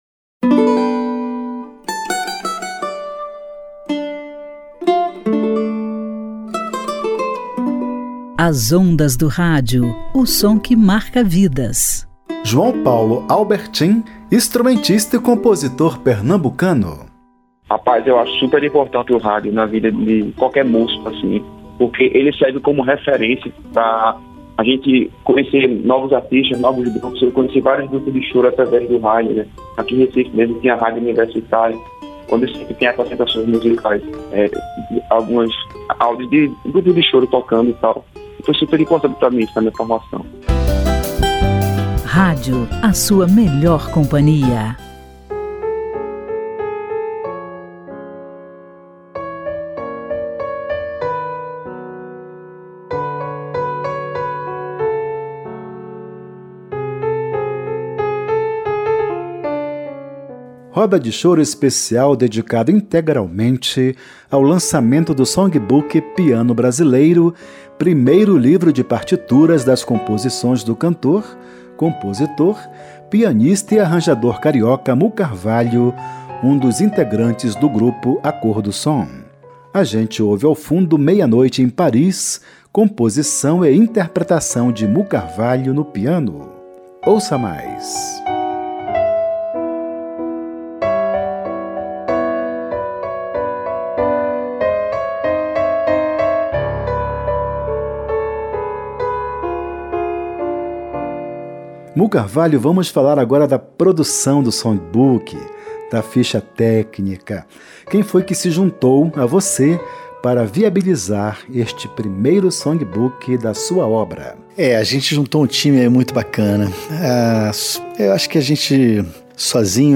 Roda de Choro mostra o lançamento do Songbook Piano Brasileiro, primeiro livro de partituras das composições do cantor, compositor, pianista, arranjador e produtor carioca Mú Carvalho. Na parte musical, 9 obras compostas e interpretadas por Mú Carvalho (piano solo).